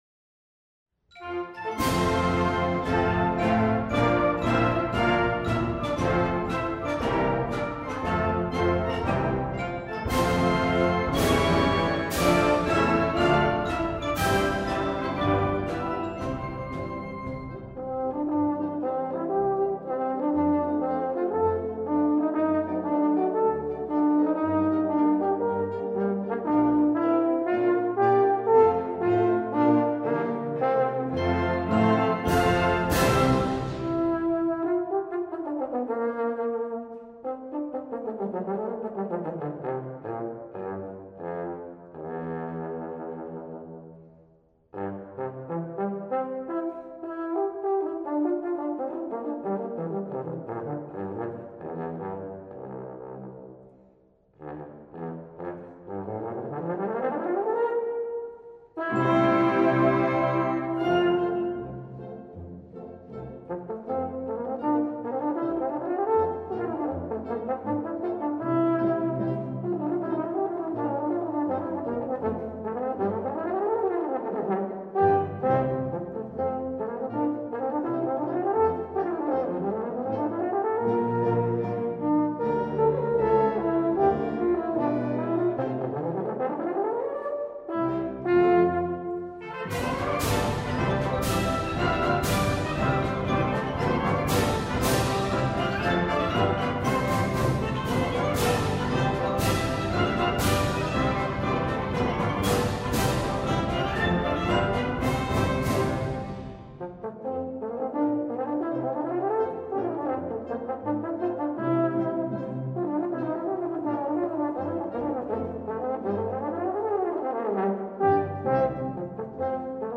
For Euphonium Solo
with Piano.